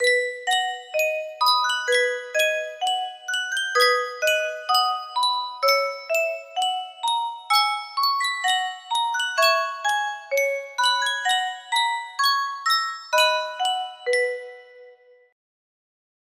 Yunsheng Music Box - Pennsylvania Fight Song 5350 music box melody
Full range 60